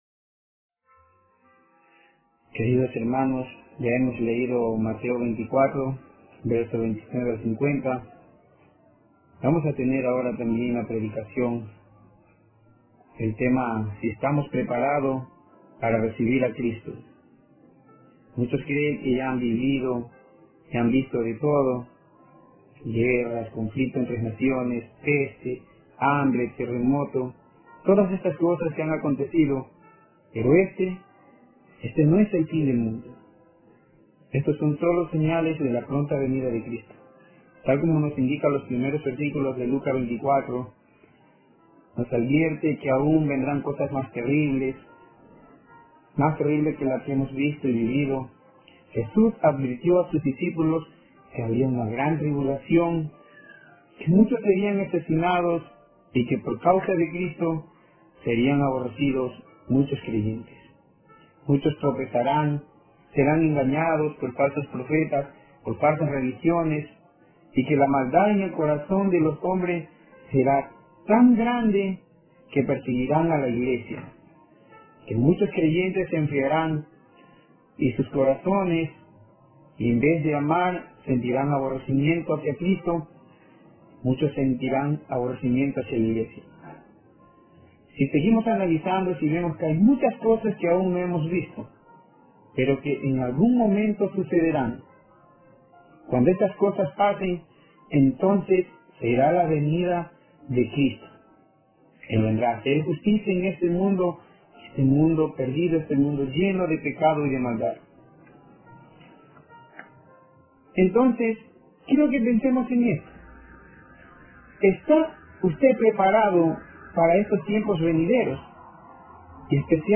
Passage: Mateo 24:29-51 Tipo: Sermón Bible Text